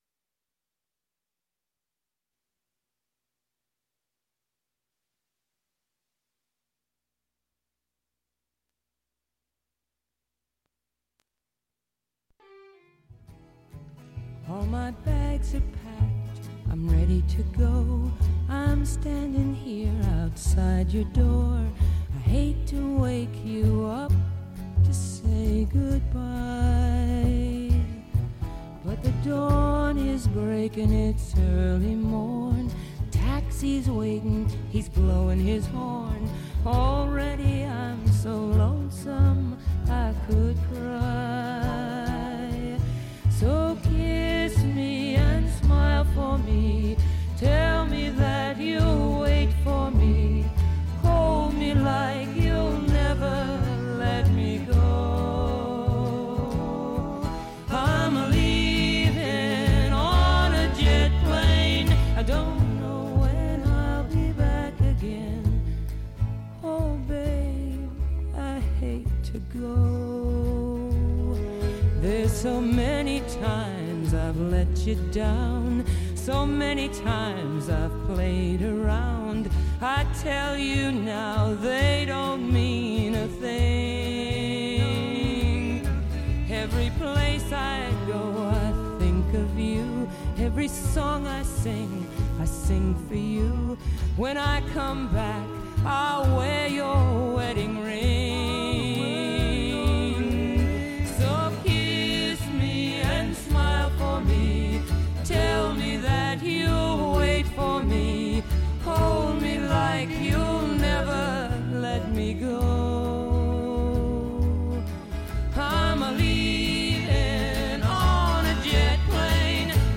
Right here on KDRT 95.7FM in Davis, California. Listening Lyrics is a genre free zone - we feature the artist.
Listen to the hope and satisfaction in their voices.